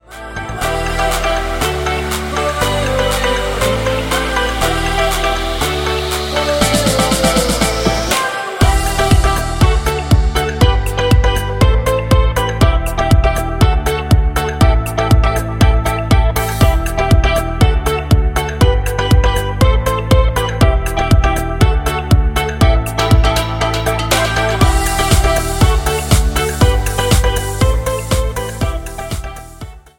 Em
MPEG 1 Layer 3 (Stereo)
Backing track Karaoke
Pop, 2010s